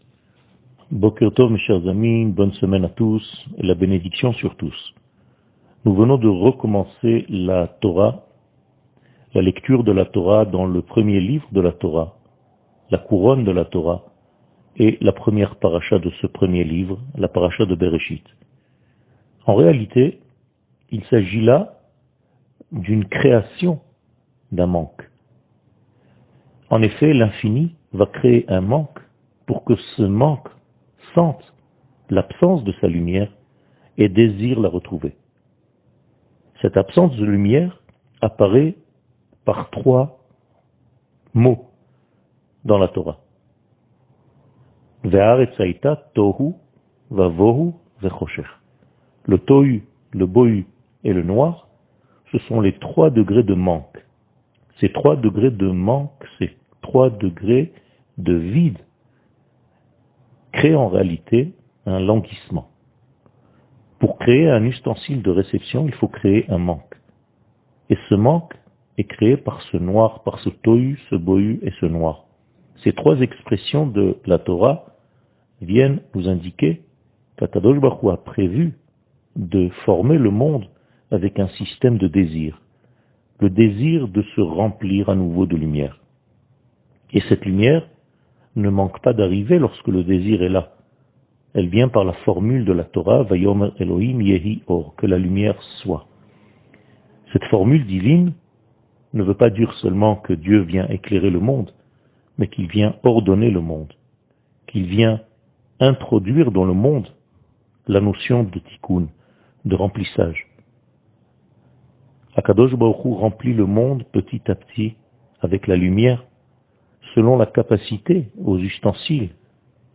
שיעור מ 03 אוקטובר 2021
שיעורים קצרים